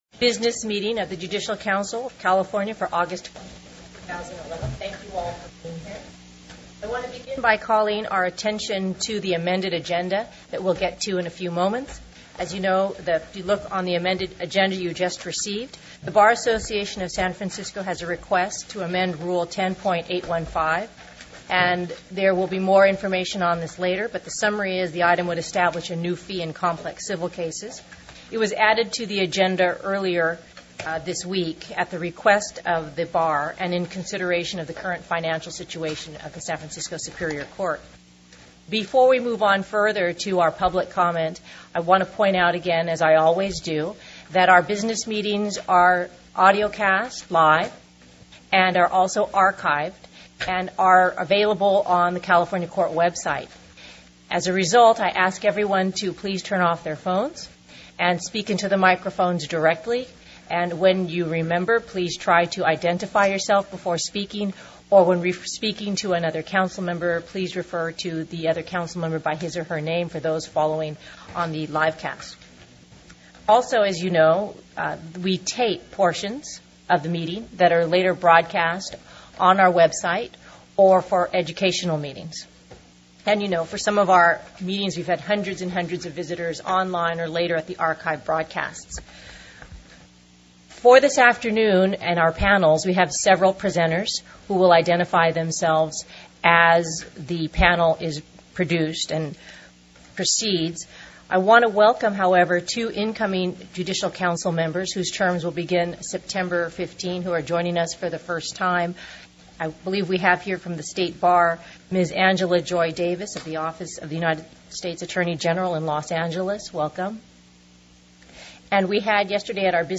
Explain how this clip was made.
Public Meeting Audio Archive (MP3)